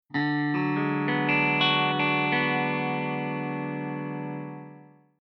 Alternate tunings used to get richer chords on a guitar
Open Bm6